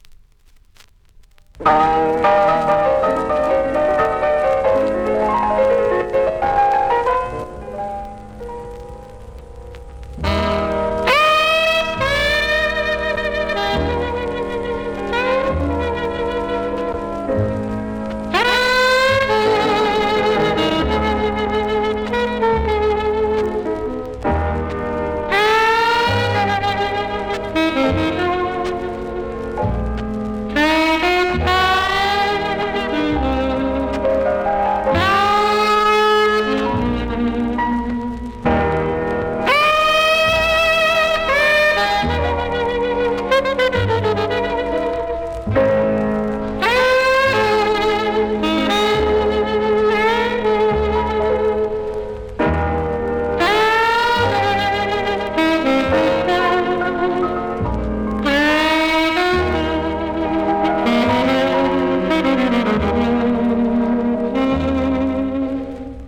INSTRUMENTAL
Vinyl
ジャマイカ盤なのでプレス起因のノイズあります。